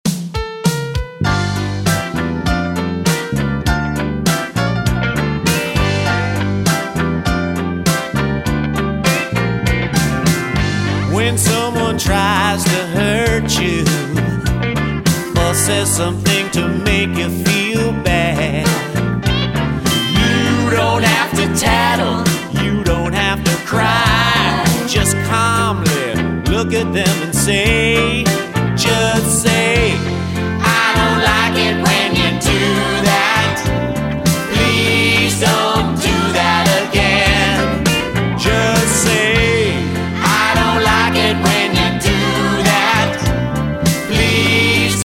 movement songs